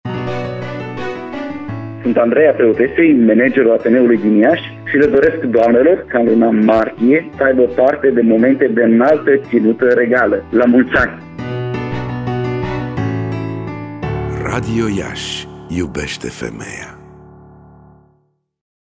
De astăzi, timp de o săptămână, pe frecvenţele de 1053 Khz, 90,8, 94,5 şi 96,3 Mghz, vor fi difuzate următoarele spoturi: